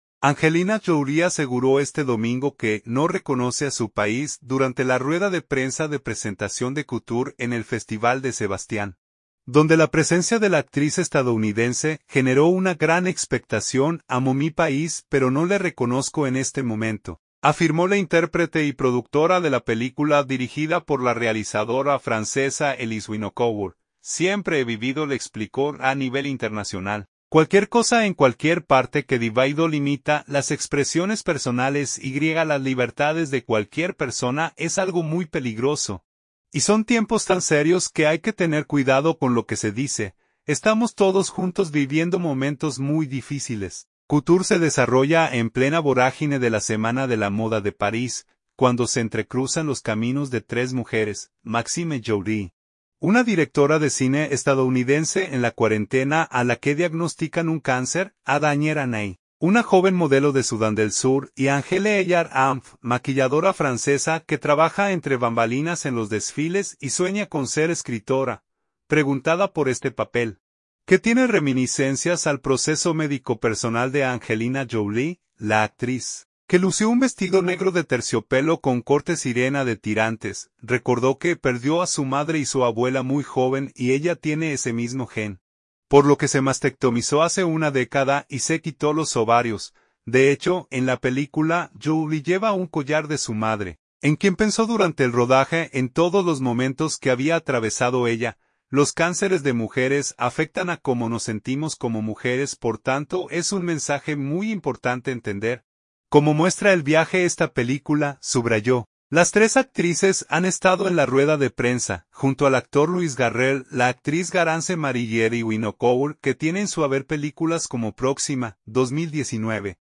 San Sebastián (España).- Angelina Jolie aseguró este domingo que no reconoce a su país, durante la rueda de prensa de presentación de ‘Couture’ en el Festival de Sebastián, donde la presencia de la actriz estadounidense generó una gran expectación.